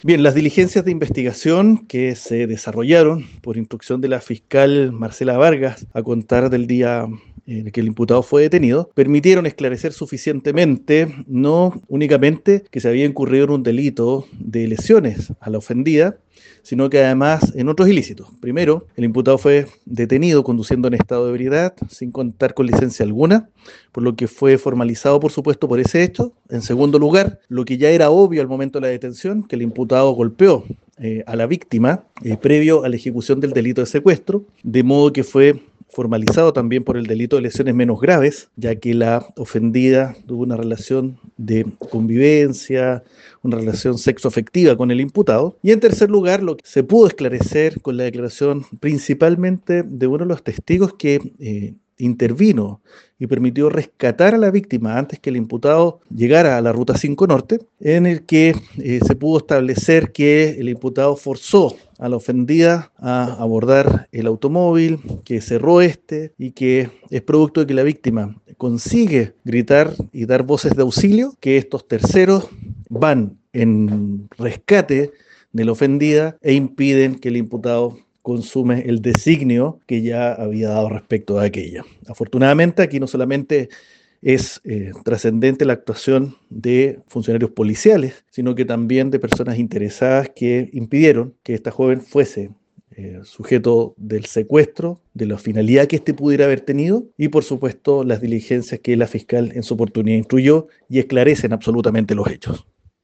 AUDIO-FISCAL-ALVARO-CORDOVA.mp3